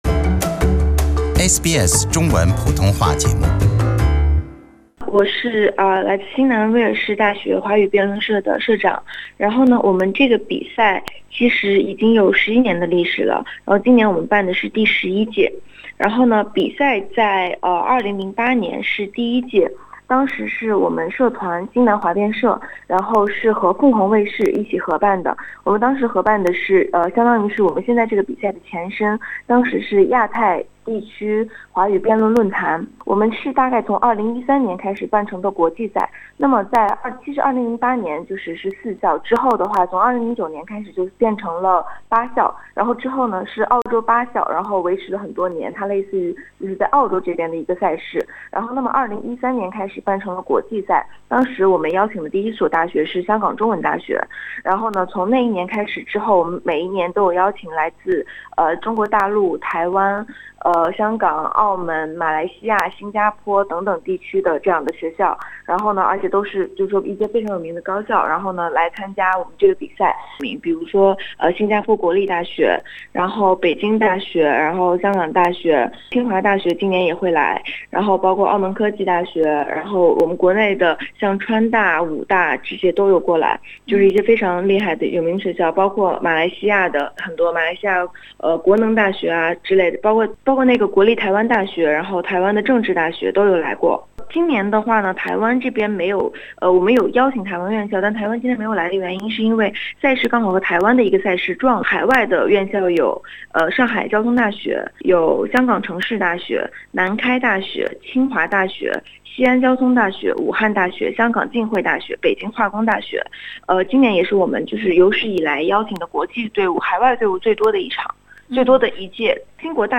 09:49 SBS 普通话电台 View Podcast Series Follow and Subscribe Apple Podcasts YouTube Spotify Download (17.98MB) Download the SBS Audio app Available on iOS and Android 2018“华夏杯”国际华语辩论锦标赛9月底将在新南威尔士大学举行。